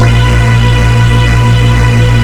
PADSTACK  -L.wav